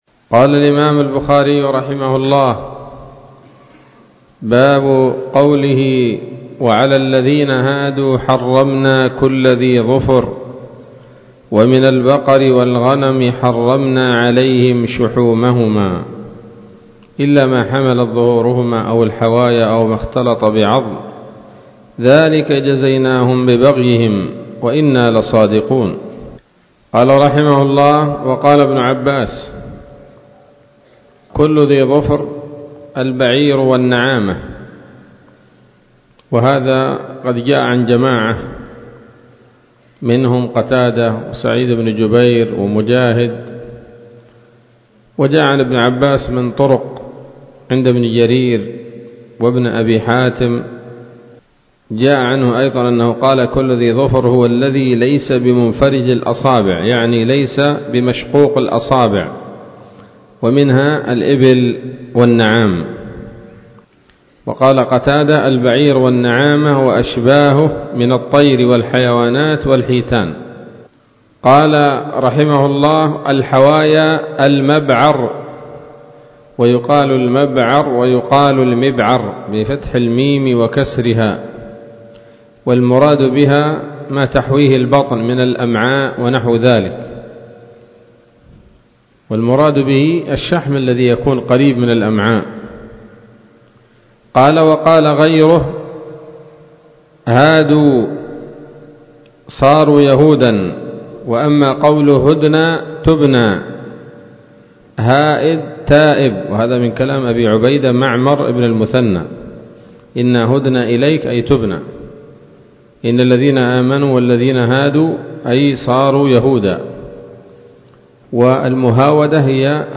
الدرس الثالث بعد المائة من كتاب التفسير من صحيح الإمام البخاري